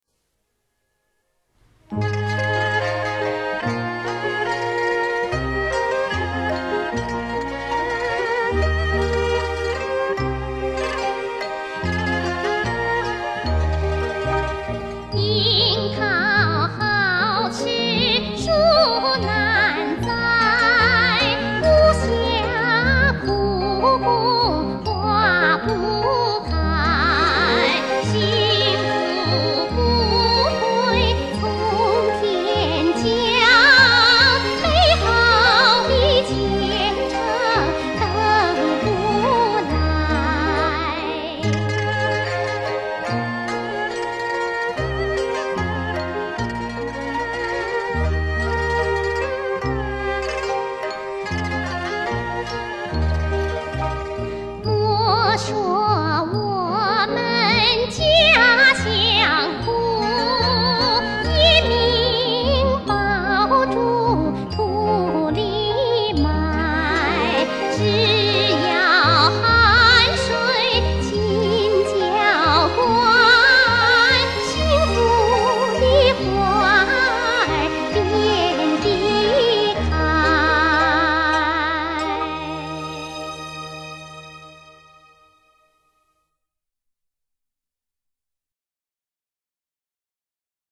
所以这首有着浓郁的山西音乐风格歌曲，听来朗朗上口，在当年非常流行，电台、音乐会上经常播放和演唱的曲目之一。